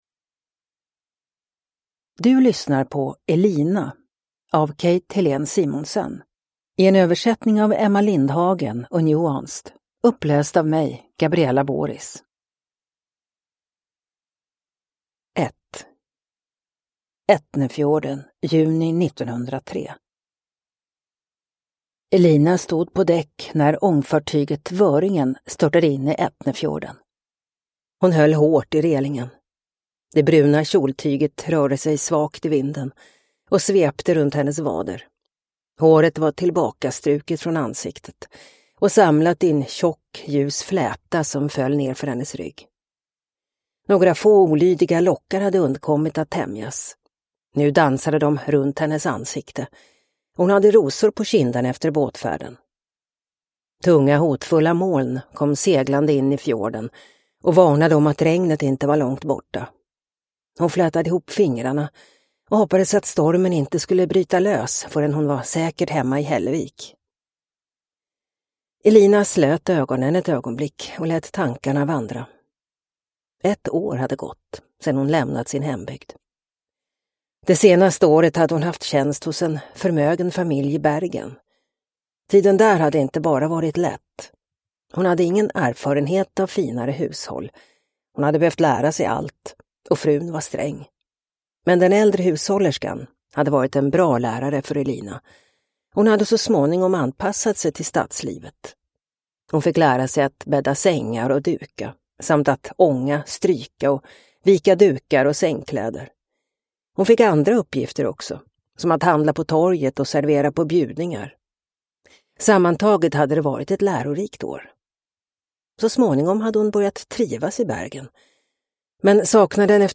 Elina – Ljudbok